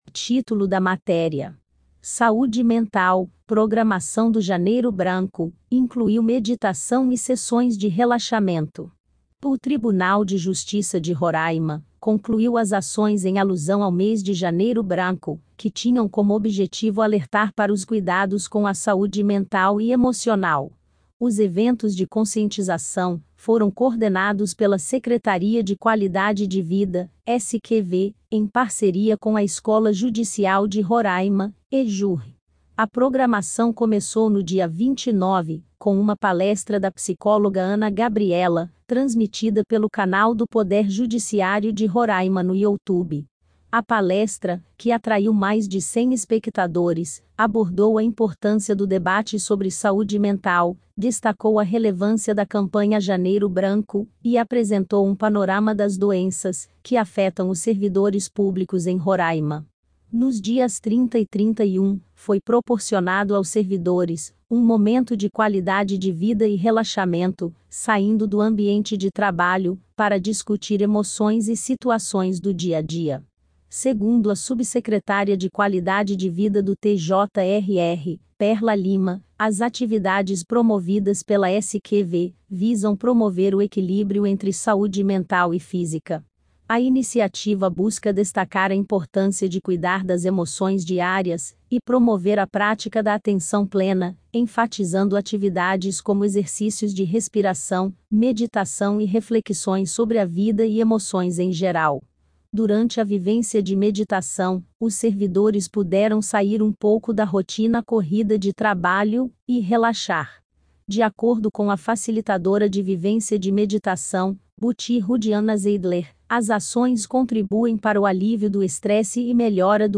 SAUDE_MENTAL_IA.mp3